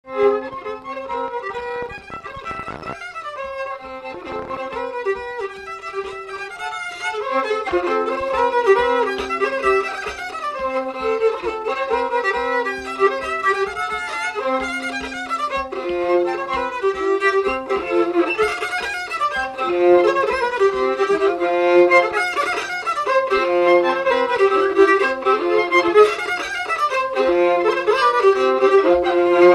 Séga
Salazie
Instrumental
danse : séga
Pièce musicale inédite